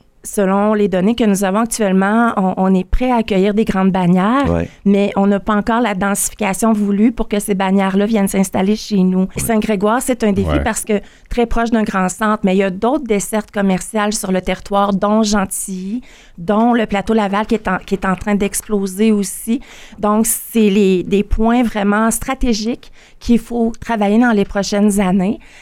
Cette dernière en a glissé quelques mots lors de son passage à l’émission VIA le matin, mardi.